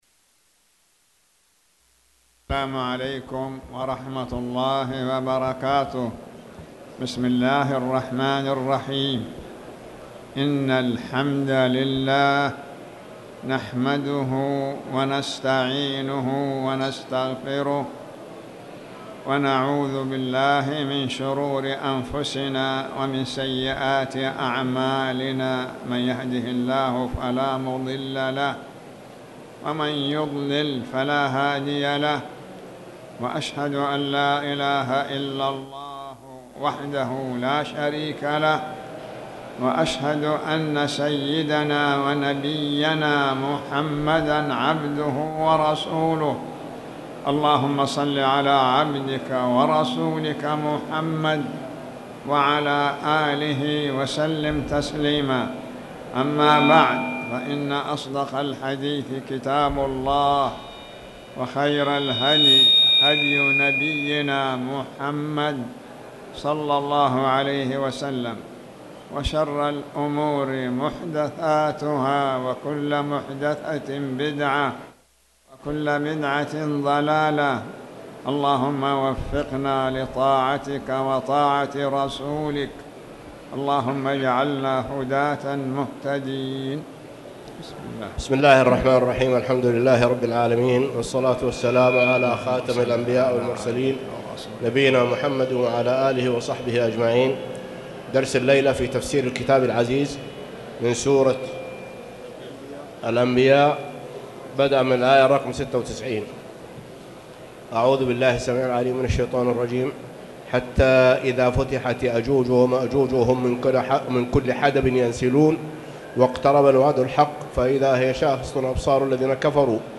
تاريخ النشر ٩ ربيع الثاني ١٤٣٩ هـ المكان: المسجد الحرام الشيخ